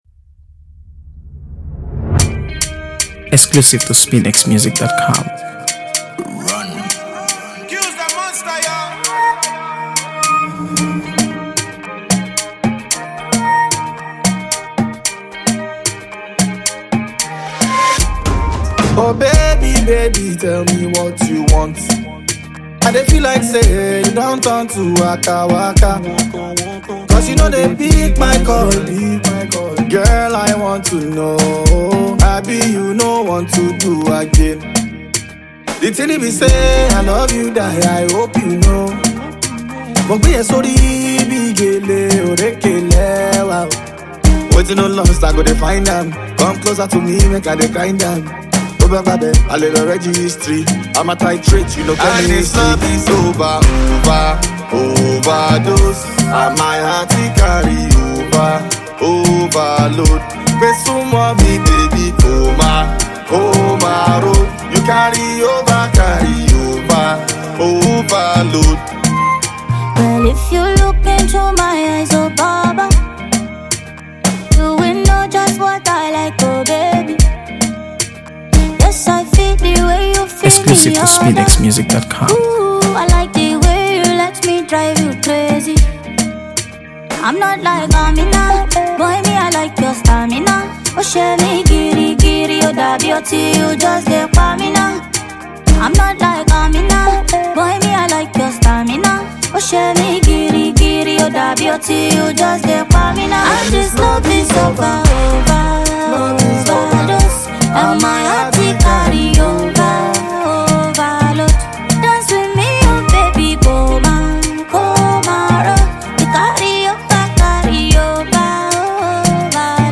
By on Afrobeat
Nigerian veteran rapper
Nigerian vocal powerhouse